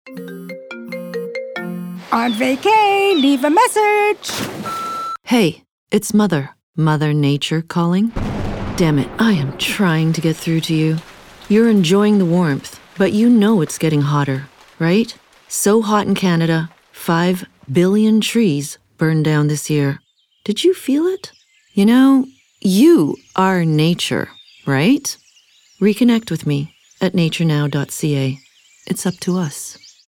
RADIO SPOTS
Nature-Agency-Angry-Radio-Mix-Dec.-5.23.mp3